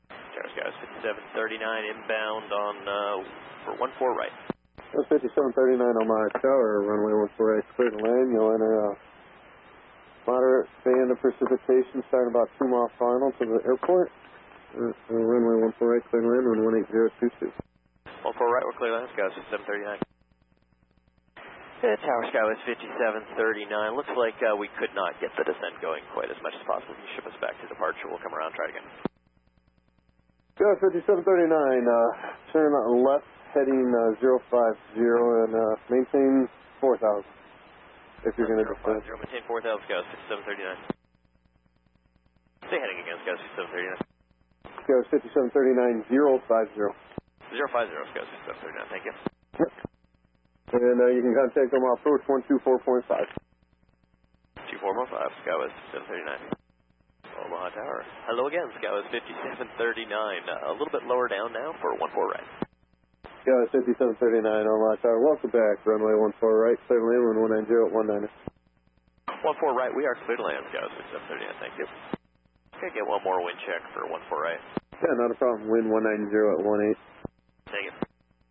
Based on trackings from FlightRadar24 and ATC recordings from LiveATC, I was able to find out that our approach was much too high and the pilot was unable to get the plane to a lower altitude in time. He then asked to go around for another try. I have included the ATC recordings below for anyone curious as to what it sounded like (it is edited for time and credit to LiveATC).